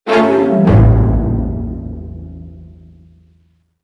乐器类/重大事件短旋律－宏大/tune2.wav
• 声道 立體聲 (2ch)